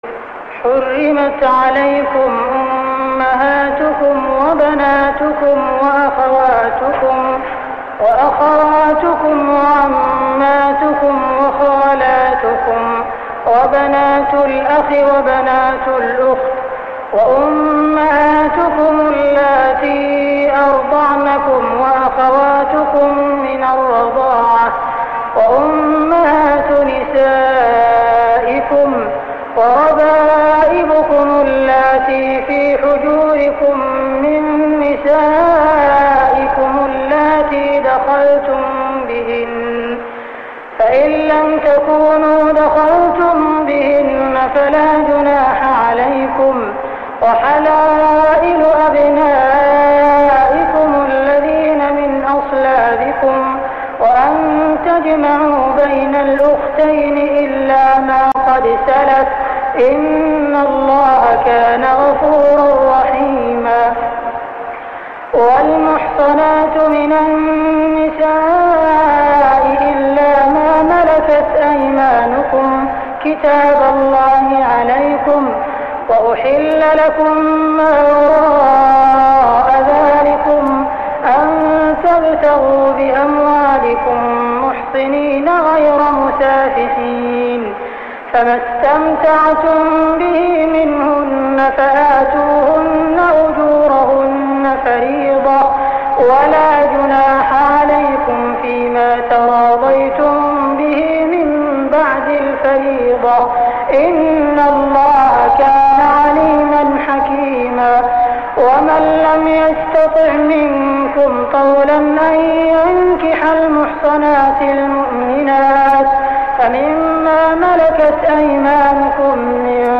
صلاة التراويح ليلة 6-9-1407هـ سورة النساء 23-93 | Tarawih Prayer Surah An-Nisa > تراويح الحرم المكي عام 1407 🕋 > التراويح - تلاوات الحرمين